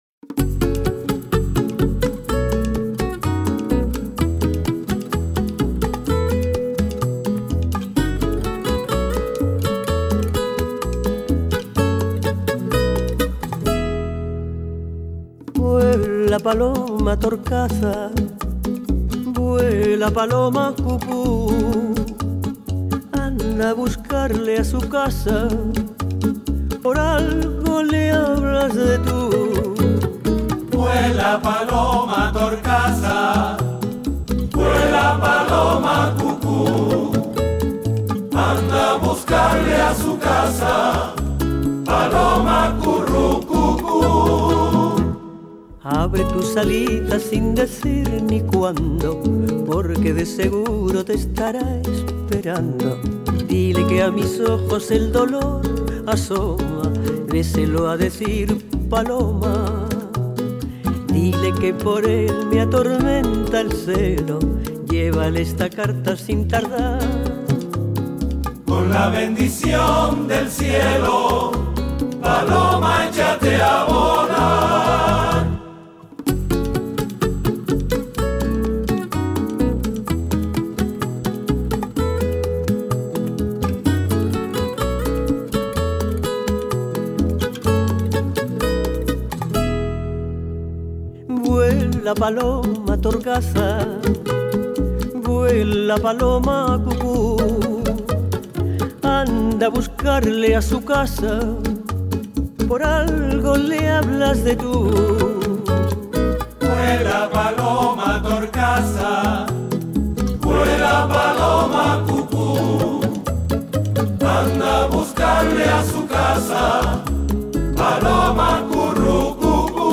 cantando en español